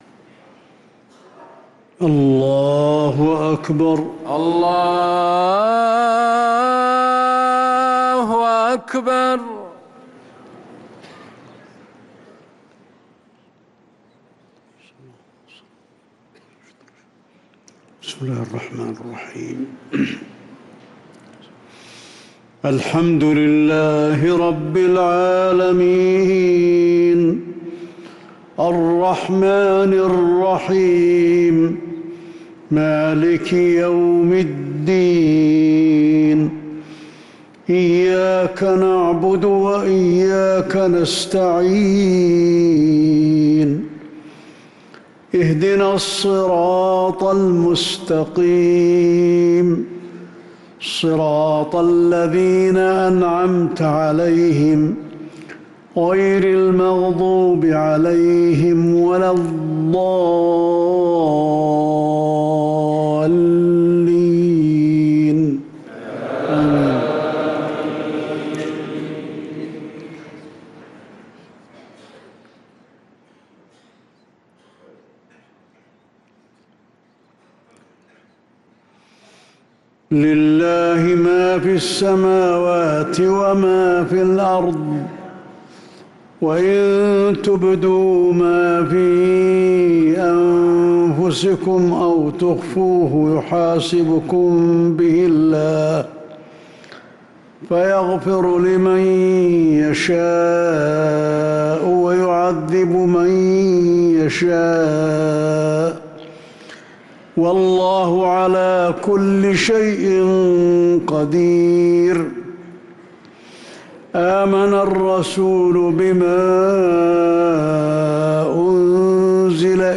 صلاة المغرب للقارئ علي الحذيفي 19 جمادي الأول 1445 هـ